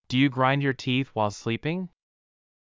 ﾄﾞｩ ﾕｰ ｸﾞﾗｲﾝﾄﾞ ﾕｱ ﾃｨｰｽ ﾜｲﾙ ｽﾘｰﾋﾟﾝｸﾞ